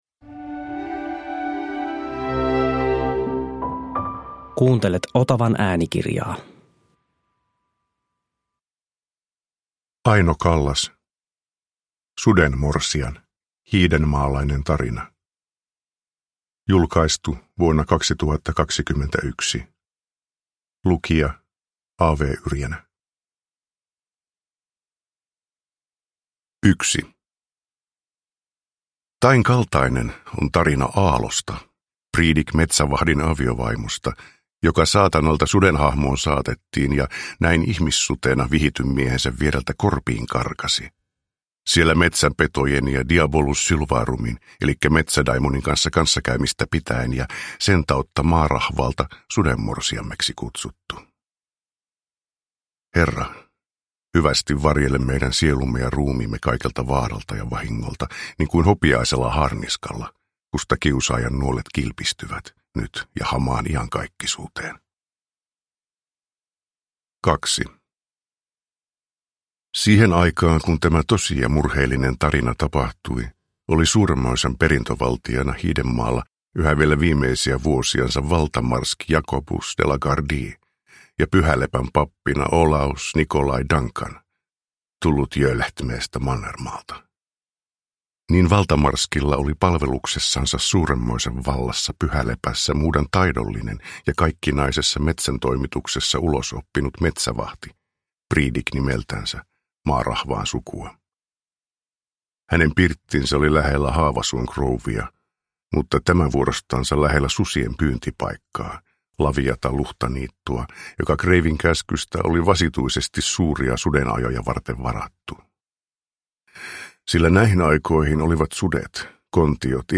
Sudenmorsian – Ljudbok – Laddas ner